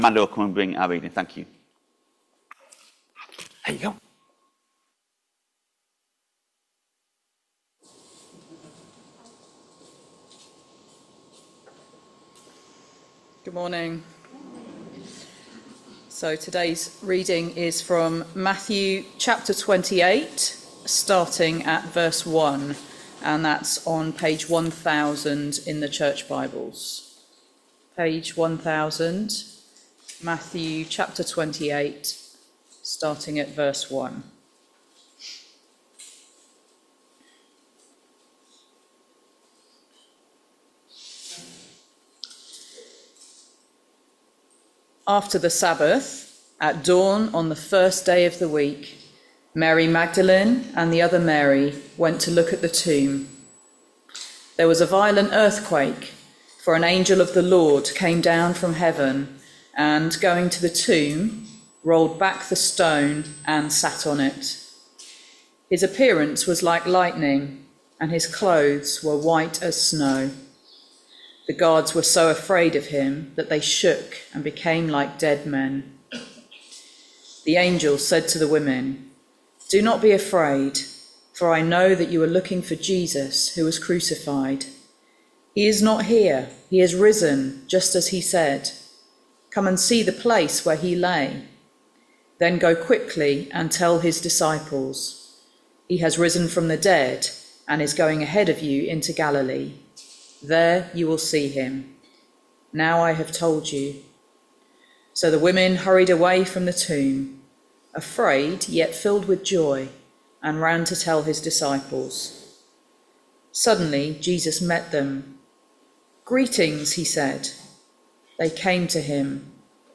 Matthew 28vv1-10 Service Type: Sunday Morning All Age Service Topics